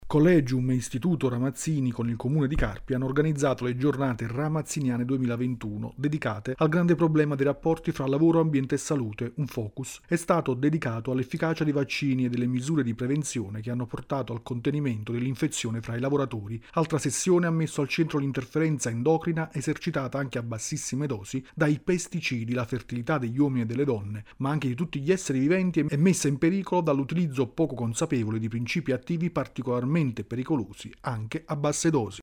A Carpi si sono svolte le Giornate Ramazziniane con l’Istituto Ramazzini, cooperativa sociale che svolge ricerca scientifica. Il servizio